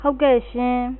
23_Yes (Female)
23_Yes-Female.wav